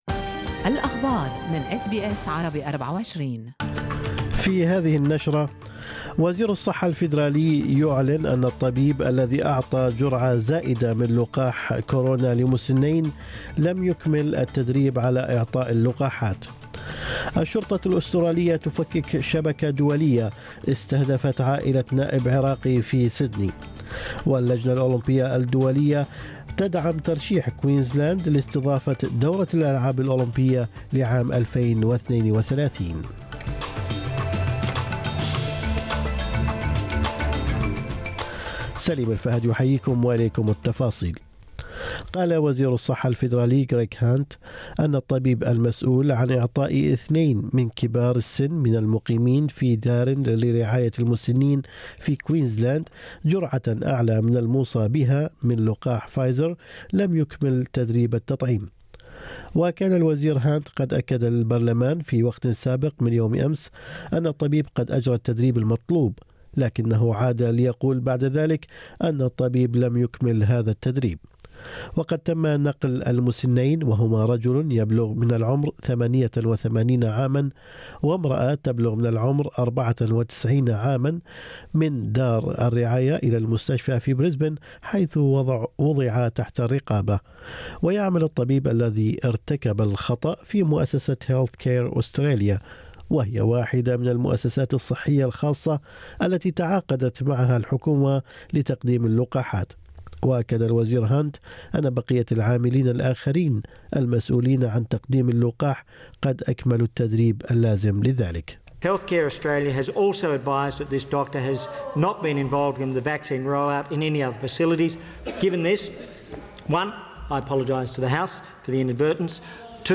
نشرة أخبار الصباح 25/2/2021